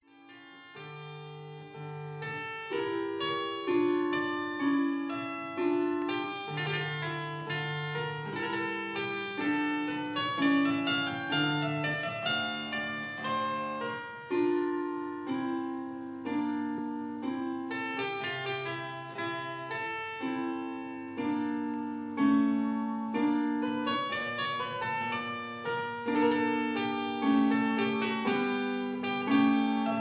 harpsichord